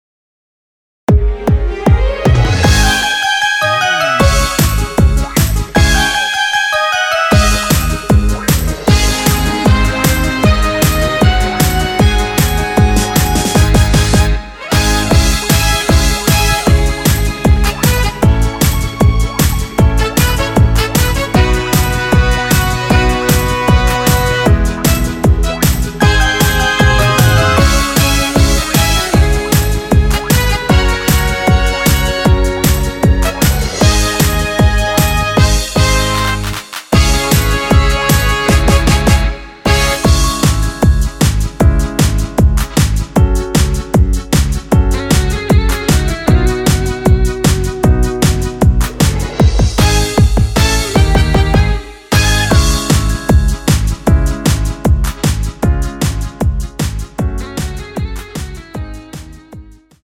원키에서(-1)내린 MR입니다.
Dbm
◈ 곡명 옆 (-1)은 반음 내림, (+1)은 반음 올림 입니다.
앞부분30초, 뒷부분30초씩 편집해서 올려 드리고 있습니다.
중간에 음이 끈어지고 다시 나오는 이유는